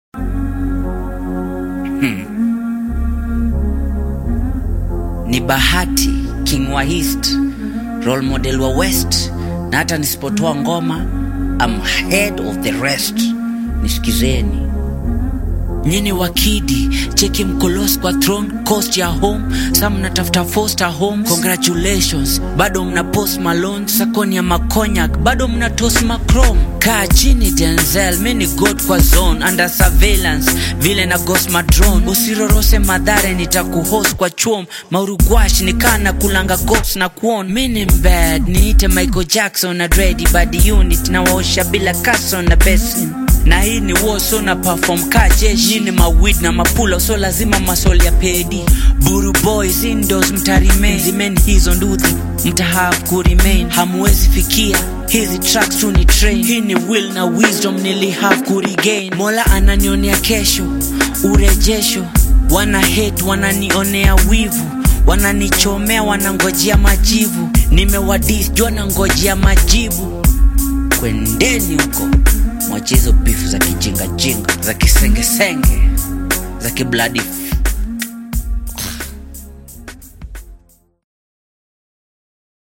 Kenyan artist
contemporary urban style